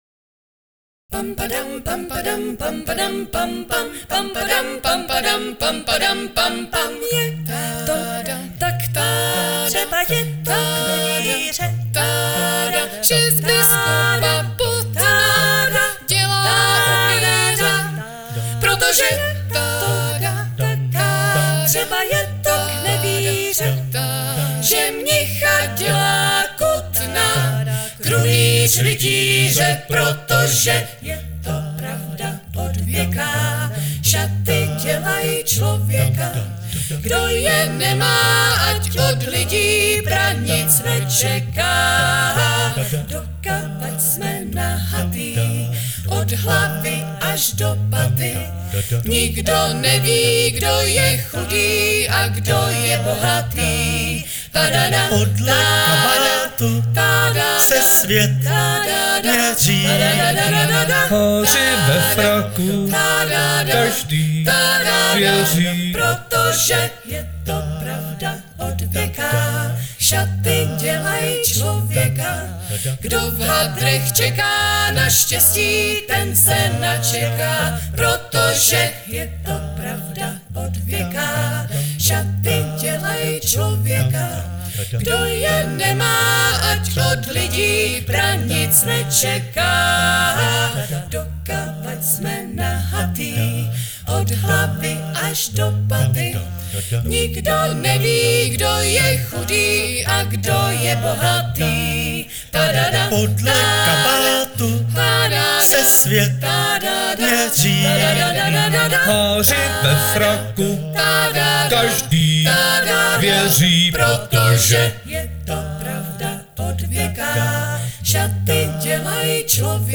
Kapela patří k předním vokálním skupinám v ČR.